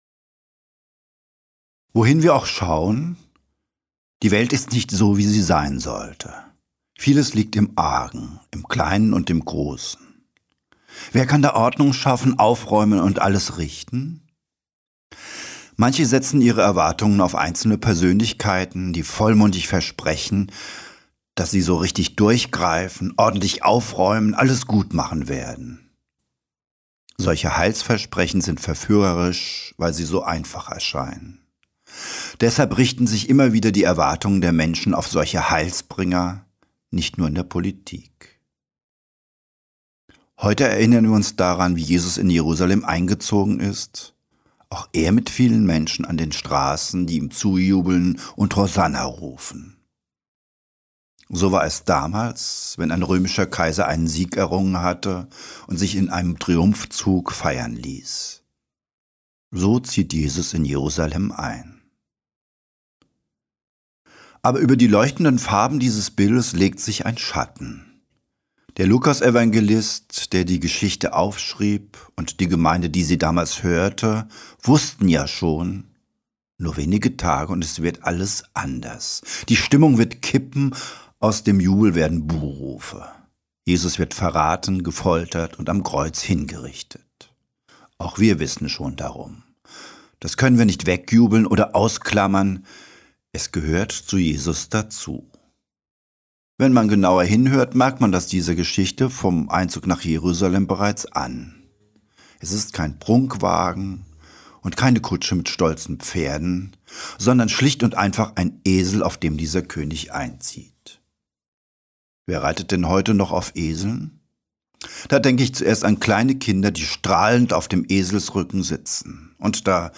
Palmsonntag-2025-Predigt-1.mp3